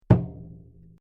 Surdo.mp3